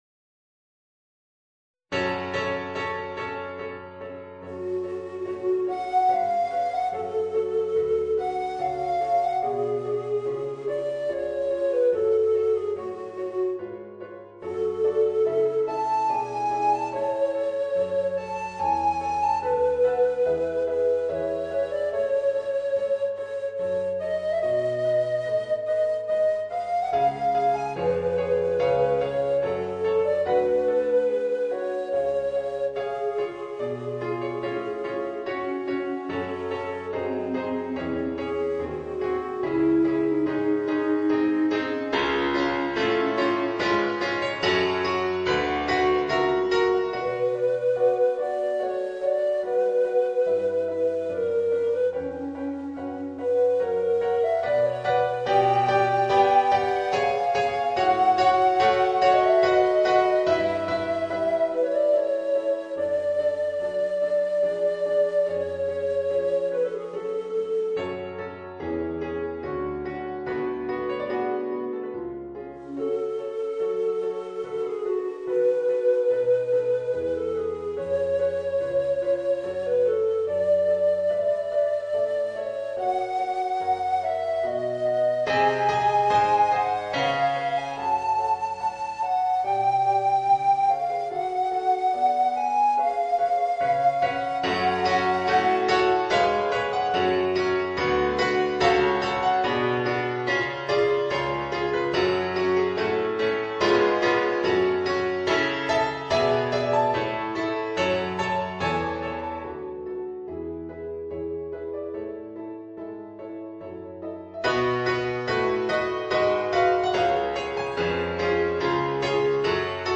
Voicing: Tenor Recorder and Piano